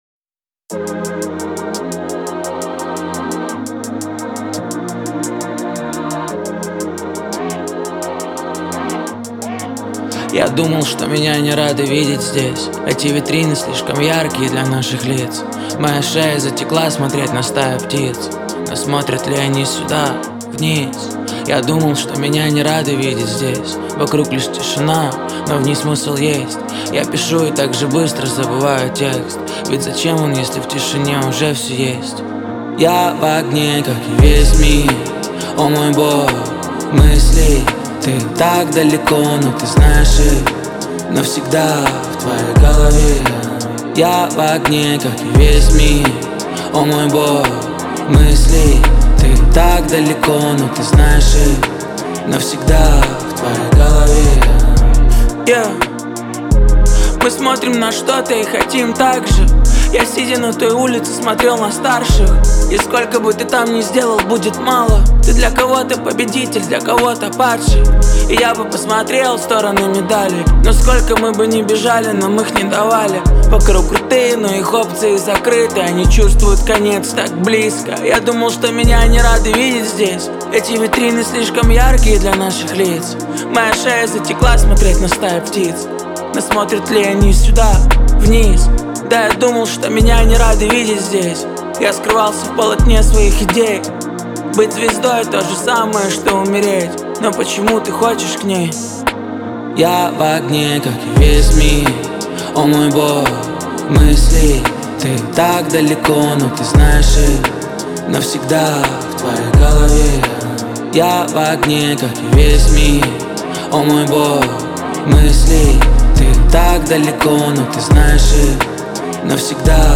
хип-хоп